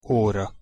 Pronunciation Hu óra (audio/mpeg)